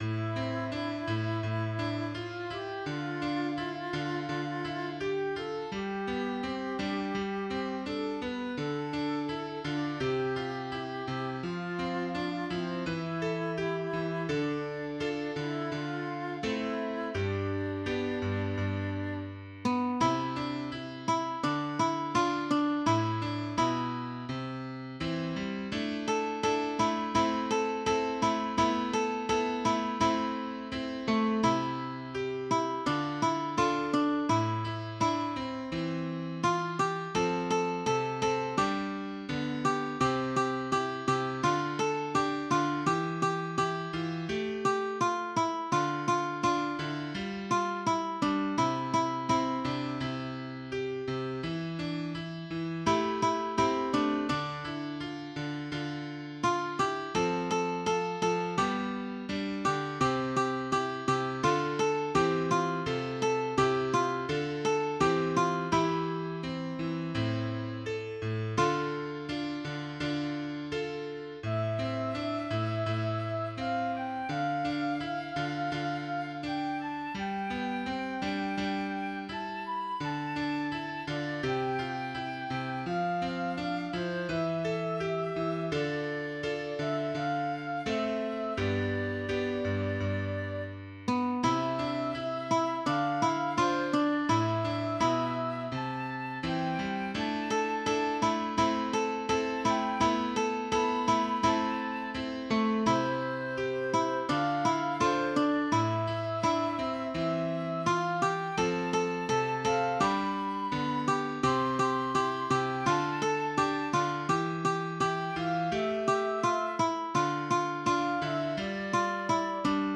Een protestlied, of een klaaglied.